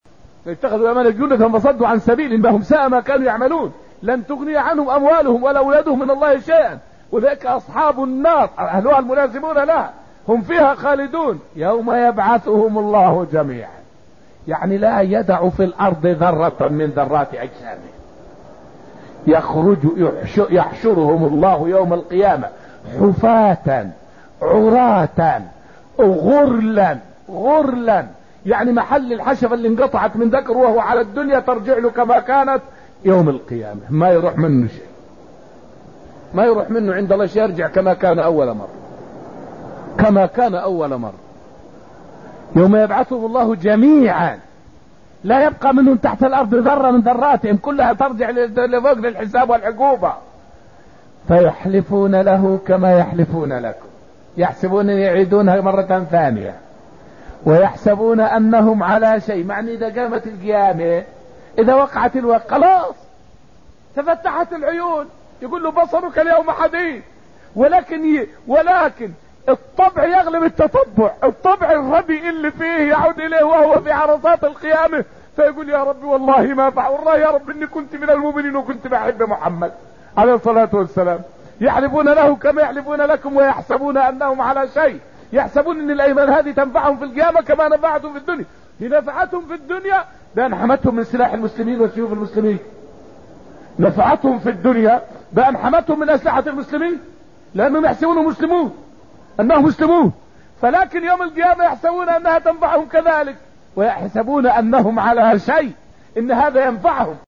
فائدة من الدرس الحادي عشر من دروس تفسير سورة المجادلة والتي ألقيت في المسجد النبوي الشريف حول موقف المنافقين يوم القيامة.